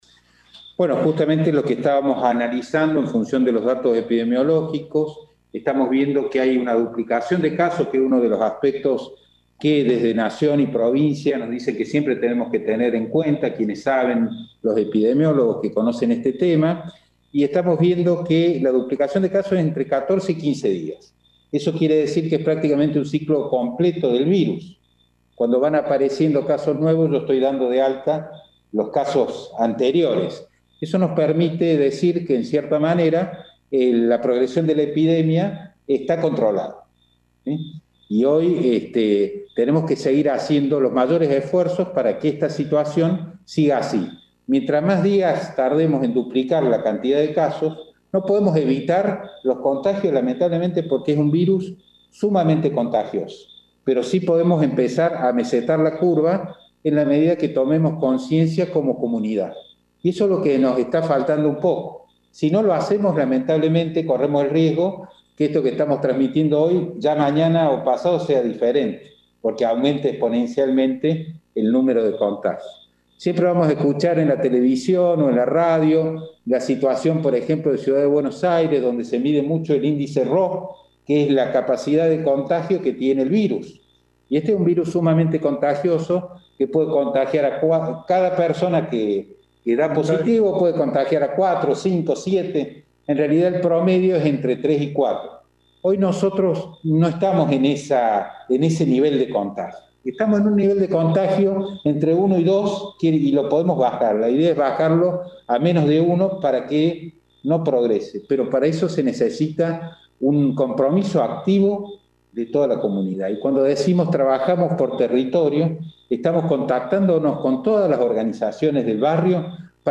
El secretario de Salud del municipio de Villa María, el Dr. Humberto Jure explicó por qué no se retrocede de fase en la ciudad.